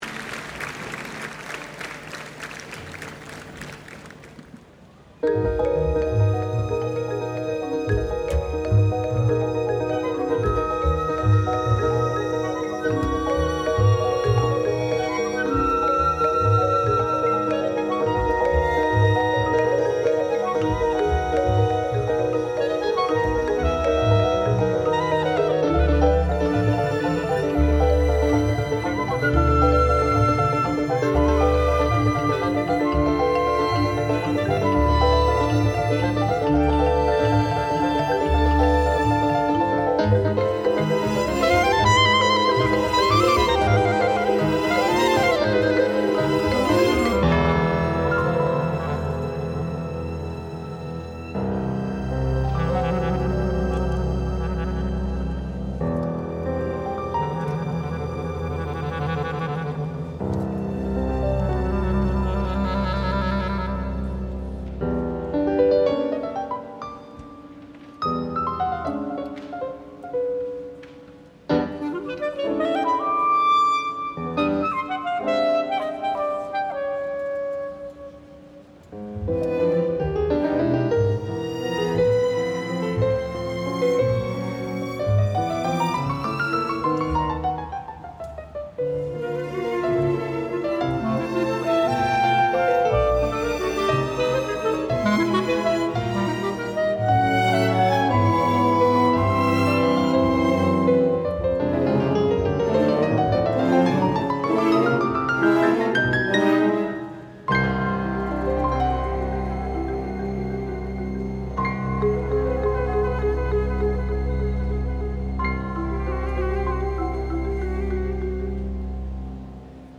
2010 world premiere performance.